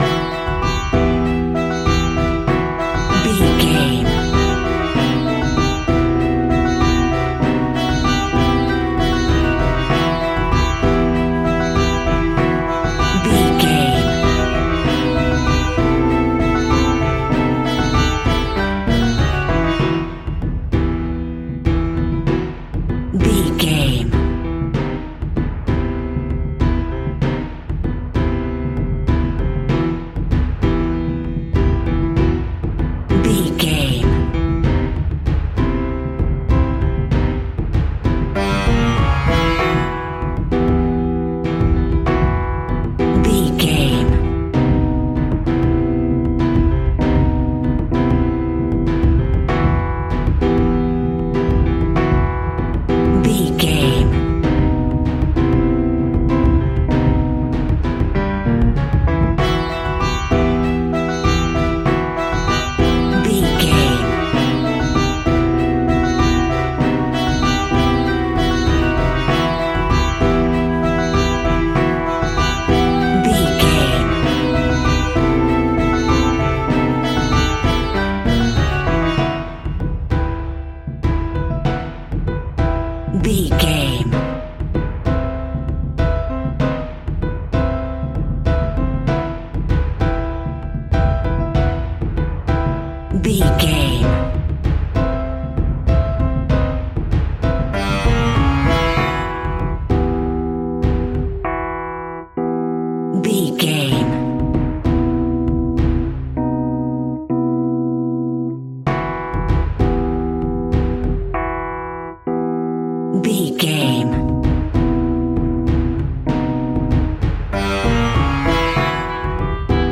Aeolian/Minor
scary
ominous
dark
haunting
eerie
strings
flute
piano
drums
synth
pads